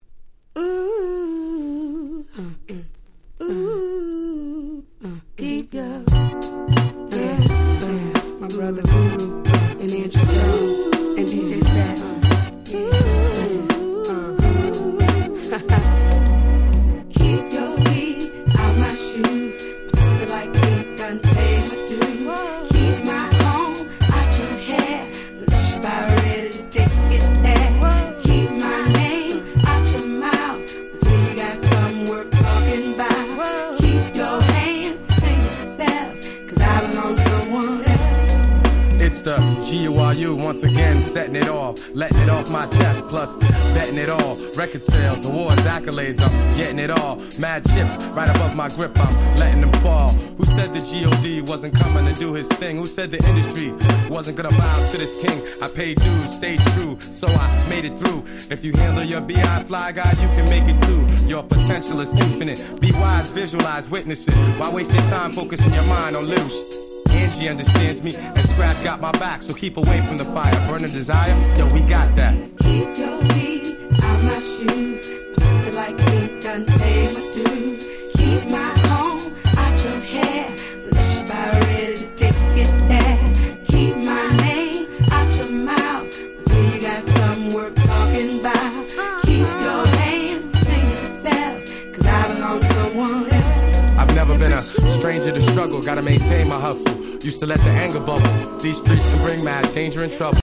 00' Very Nice Hip Hop !!